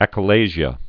(ăkə-lāzhə)